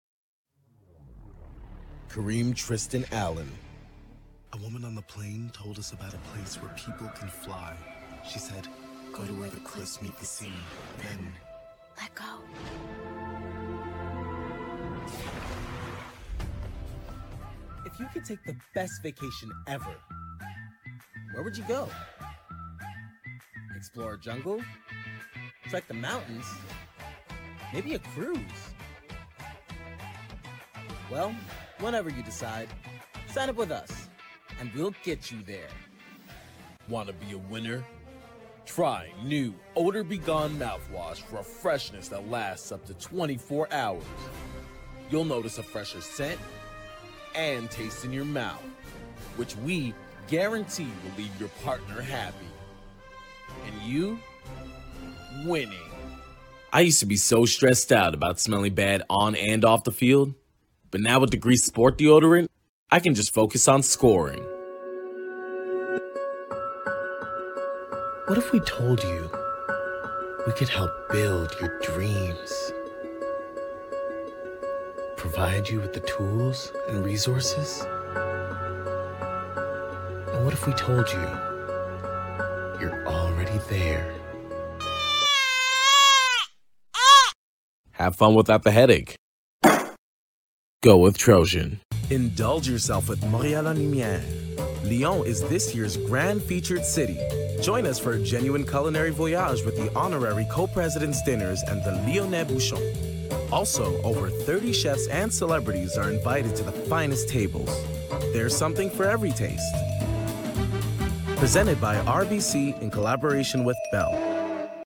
Commercials - EN